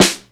southernsnr.wav